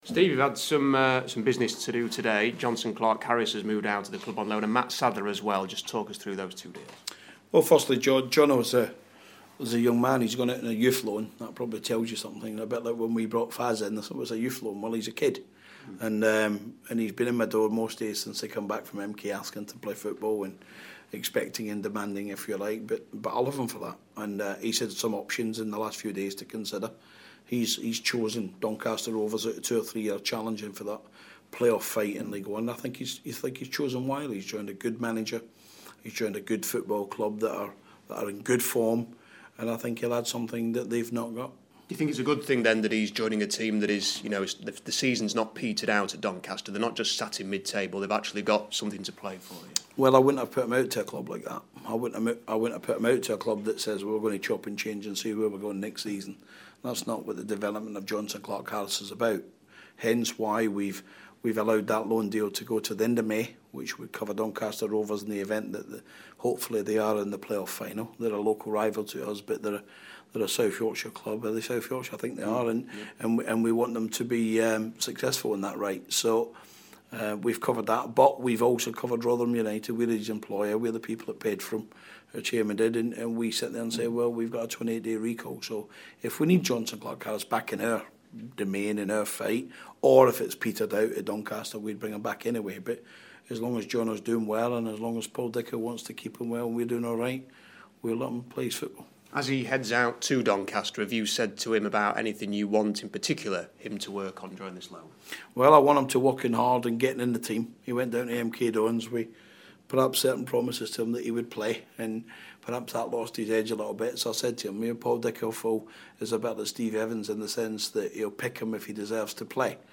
INTERVIEW: Rotherham United manager Steve Evans ahead of their relegation battle with Wigan